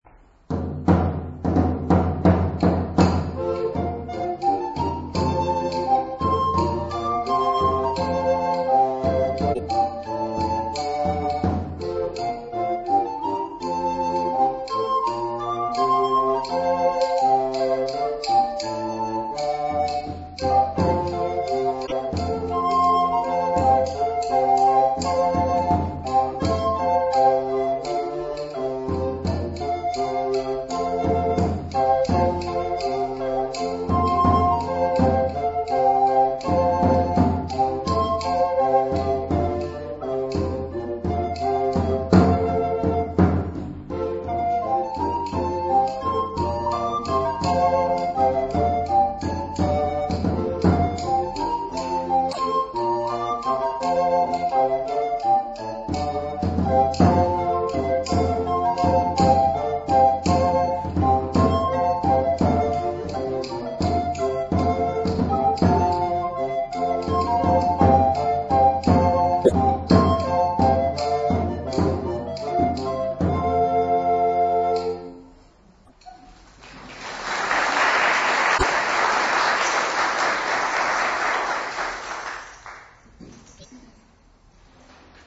Camerata California is a vocal and instrumental ensemble of the South San Francisco Bay area, dedicated to performing sacred and secular music from the medieval through the early Baroque eras.
Click to hear Camerata play
In addition to a wide variety of vocal music, Camerata performances include recorders, crumhorns, cornamuse, shawm, rankett, sackbut, viola da gamba, psaltery, and harpsichord.